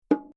Toms.mp3